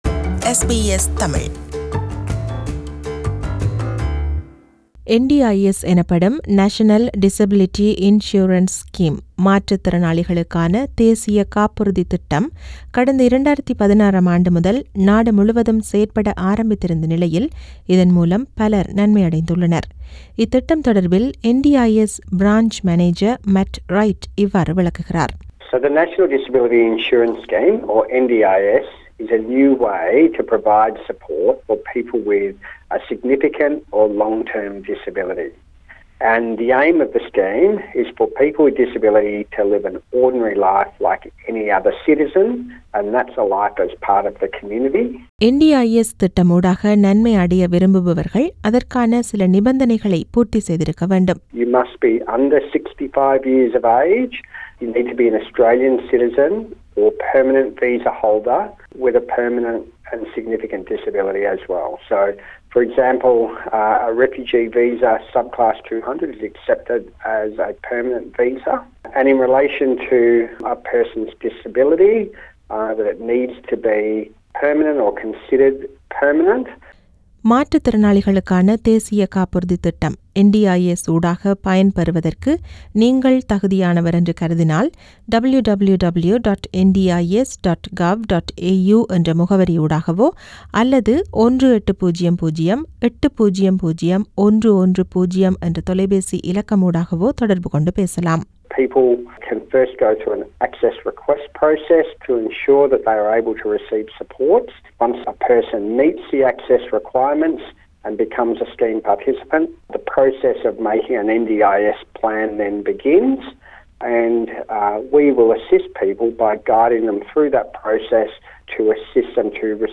NDIS எனப்படும் மாற்றுத்திறனாளிகளுக்கான தேசிய காப்புறுதி திட்டம் குறித்த தகவல்கள் அடங்கிய செய்தி விவரணம்.